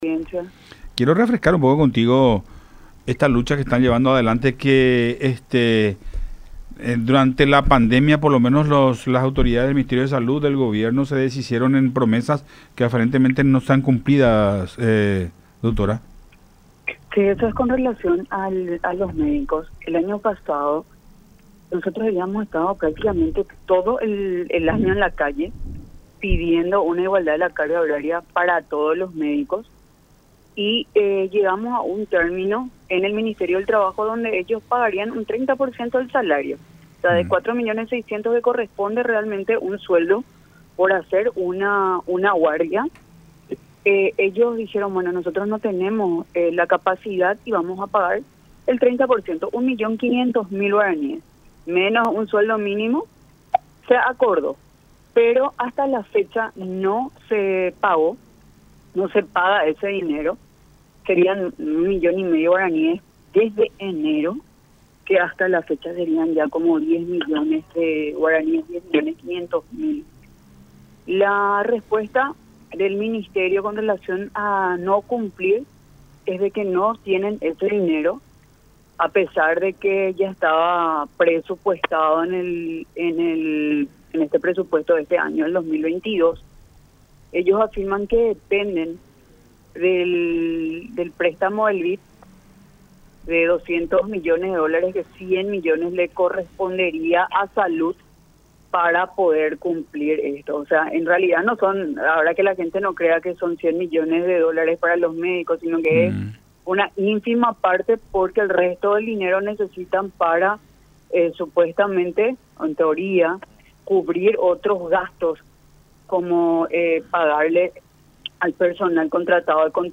en conversación con Nuestra Mañana por Unión TV y radio La Unión.